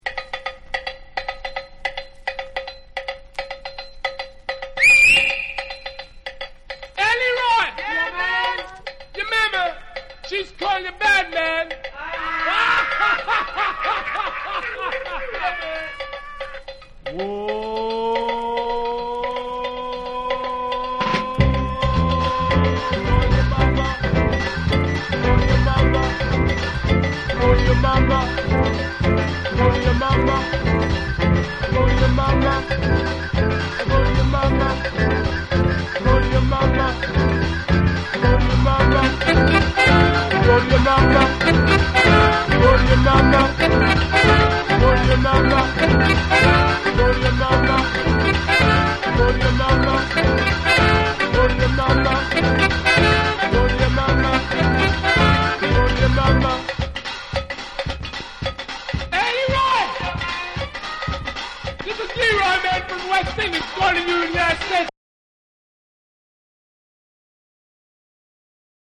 （特有のチリノイズ有ります）
REGGAE & DUB